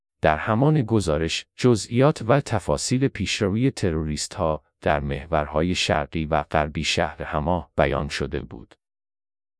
Text-to-Speech